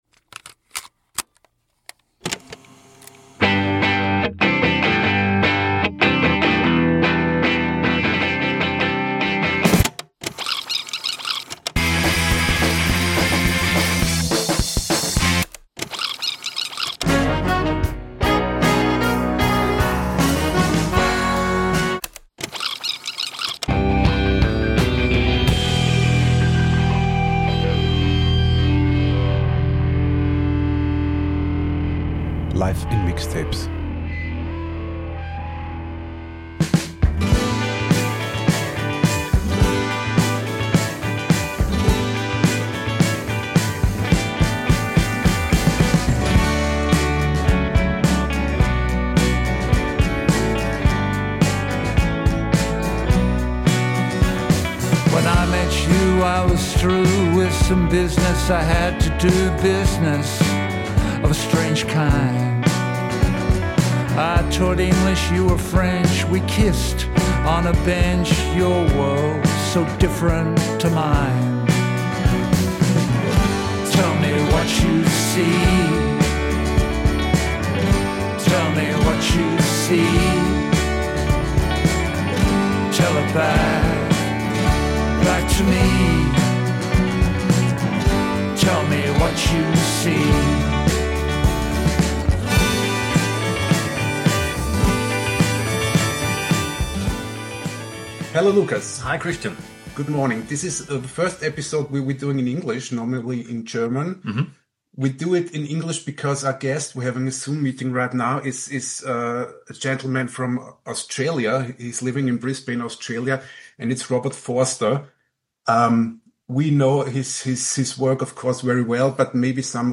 Our interview with Robert Forster: It’s about his new album, his songwriting, the role of memories in his work, explicit lyrics, the rules of rock ’n’ roll, his love for Vienna, and much more. Robert lives in Brisbane, Australia, so the interview was conducted via Zoom.